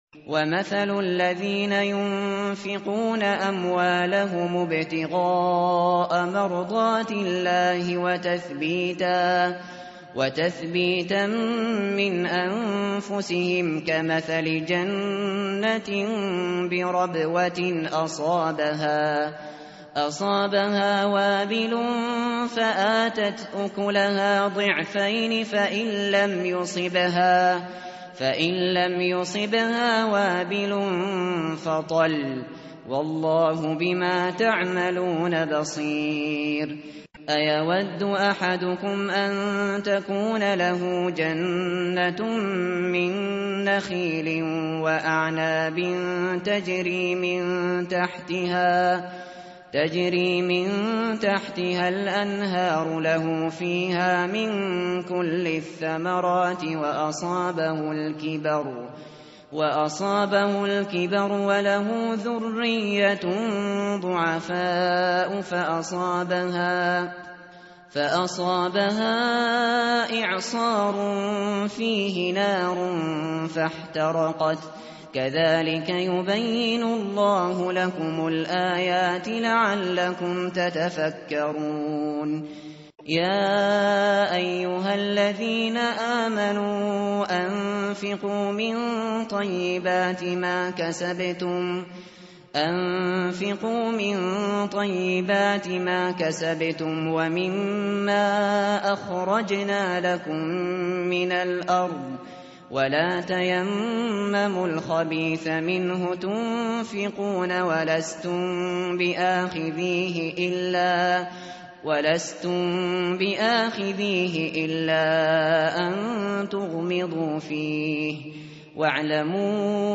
متن قرآن همراه باتلاوت قرآن و ترجمه
tartil_shateri_page_045.mp3